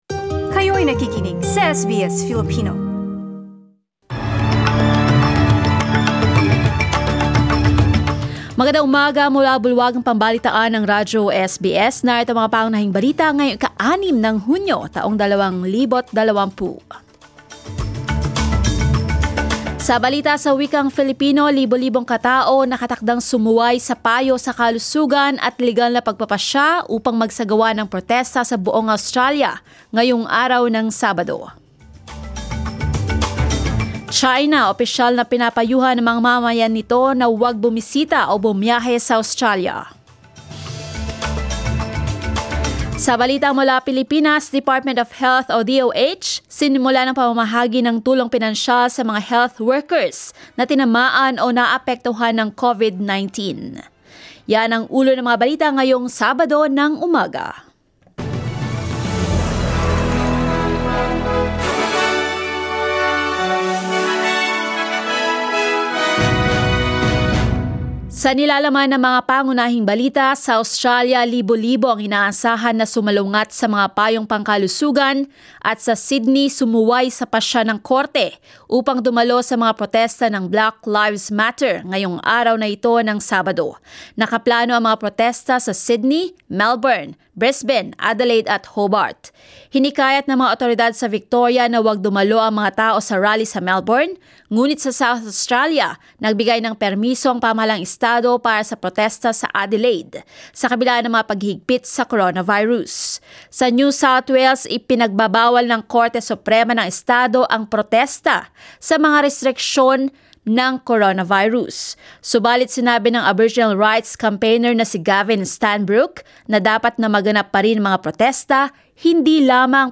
SBS News in Filipino, Saturday 06 June